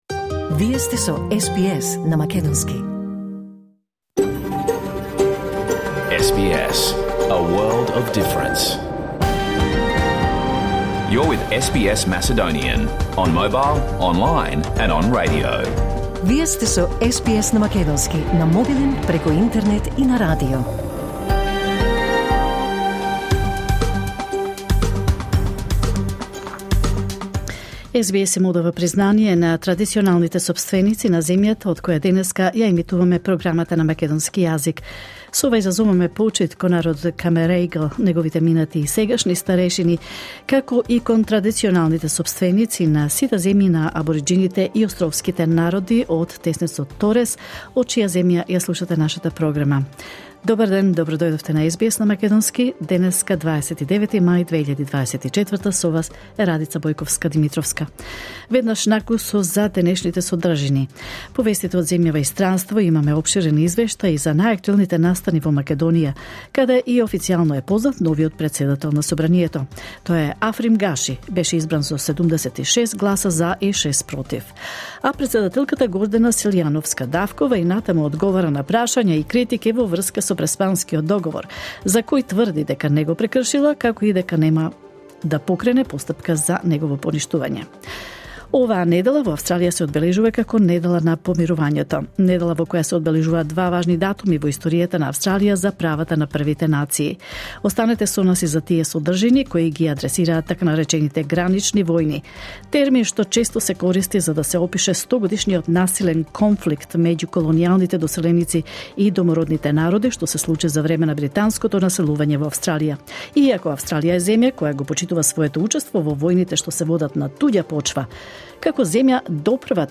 SBS Macedonian Program Live on Air 29 May 2024